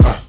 Kick 9 [ im just being honest ].wav